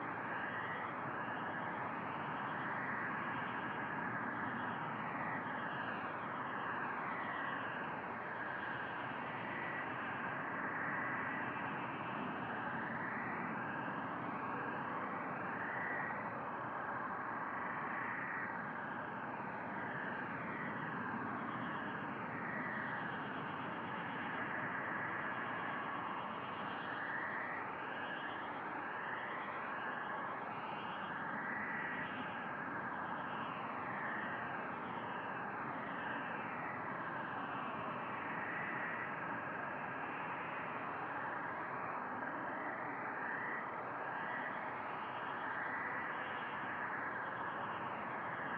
base-wind-gleba-night.ogg